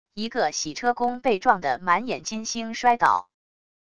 一个洗车工被撞得满眼金星摔倒wav音频